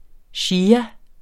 Udtale [ ˈɕiːa ]